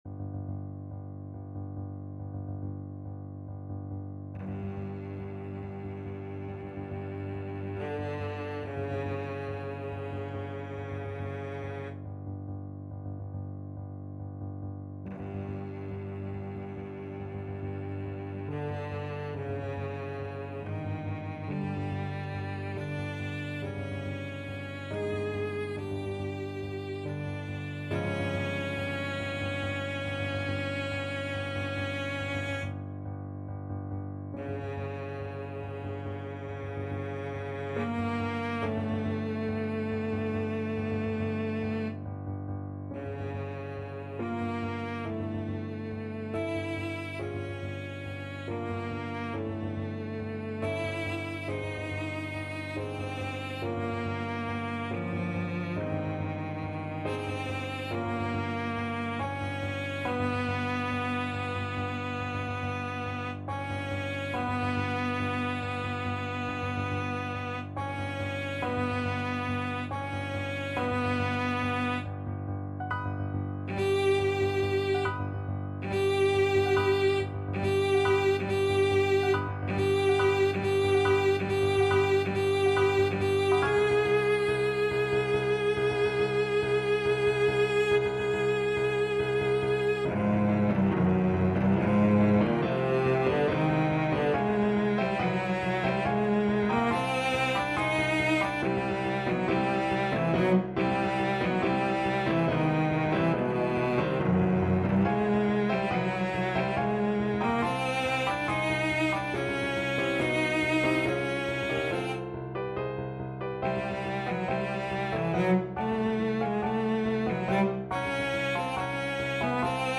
5/4 (View more 5/4 Music)
Allegro = 140 (View more music marked Allegro)
Classical (View more Classical Cello Music)